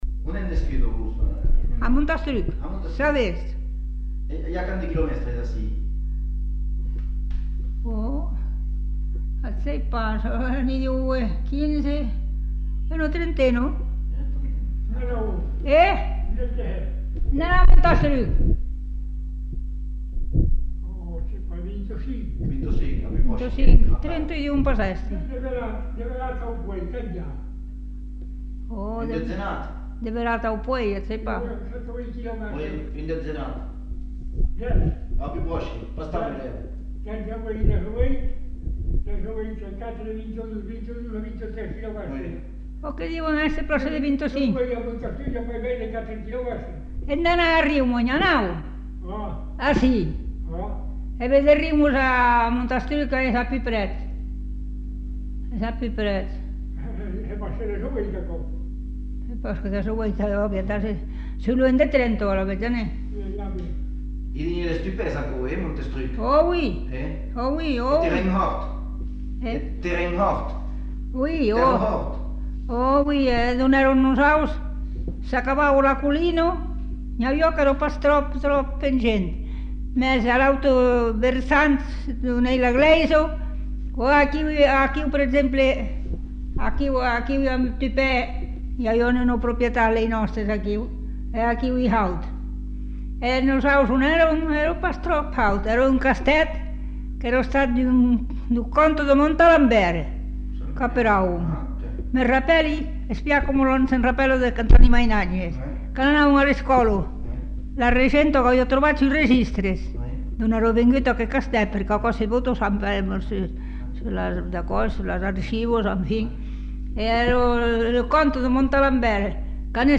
Lieu : Lherm
Genre : témoignage thématique